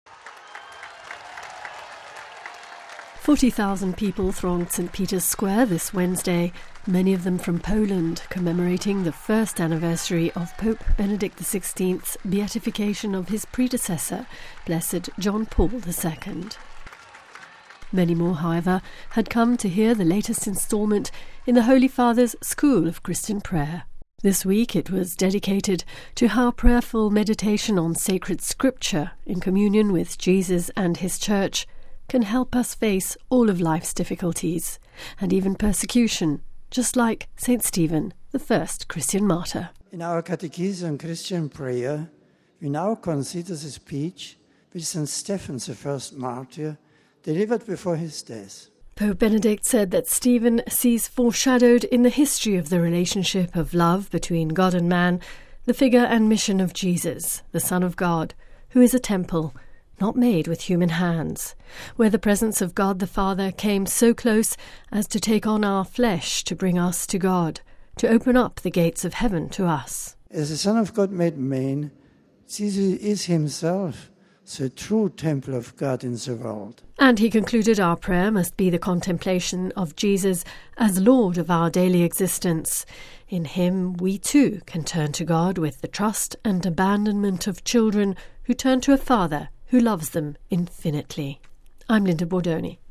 Forty thousand people thronged St Peter’s Square, and speaking to English pilgrims the Pope noted: “Stephen’s words are clearly grounded in a prayerful re-reading of the Christ event in the light of God’s word”.